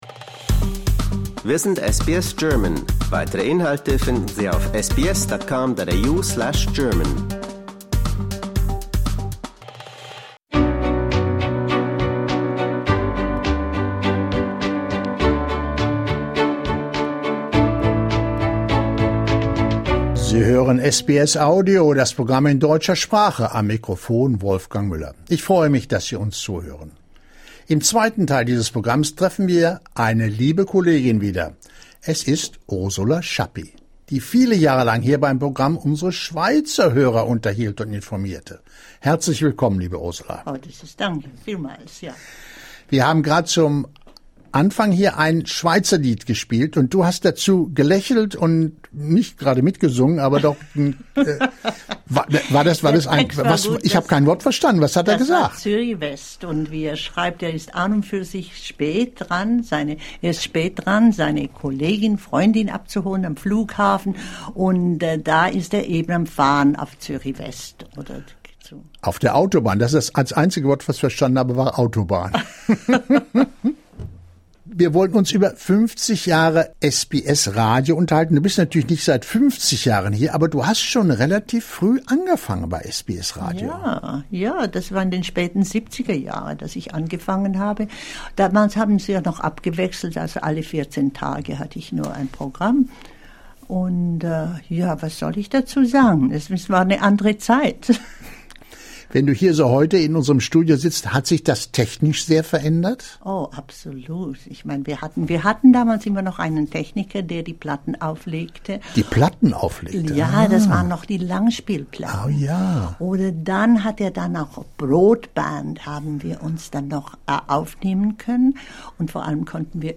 Discover more stories, interviews, and news from SBS German in our podcast collection .